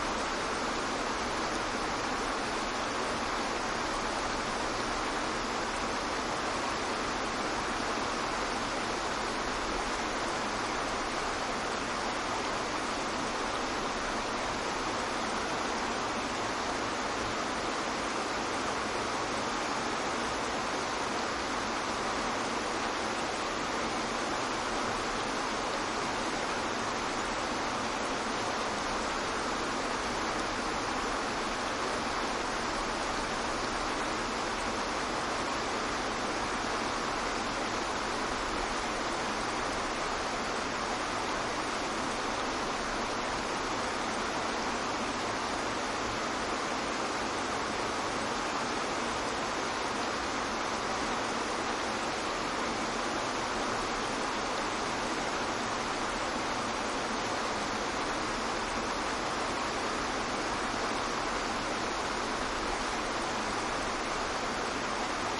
房间色调/氛围 " 河流的流动和氛围 01
描述：用Tascam DR40密切记录的河流
标签： 湍急 河流 环境
声道立体声